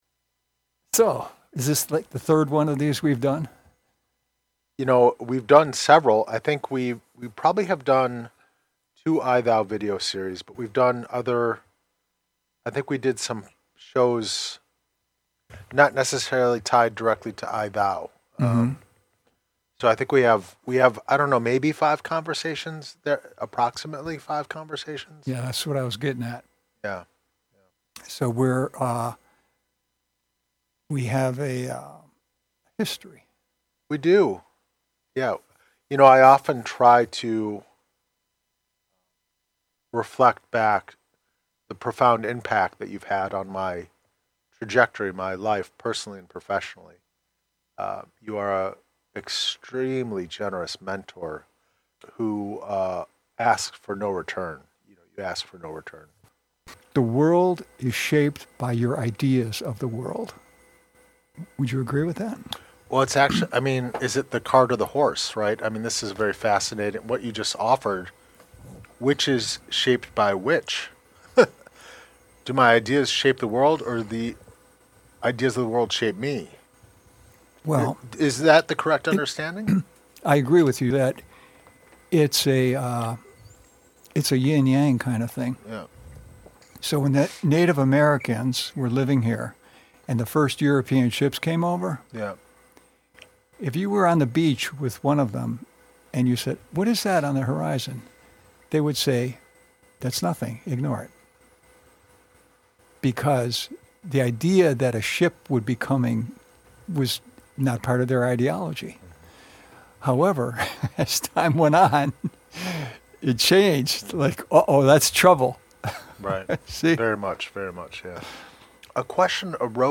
Mentorship, Reciprocity, Reality's Backdrop, and Panta Rhei (Everything Changes): An I Thou Video Series Conversation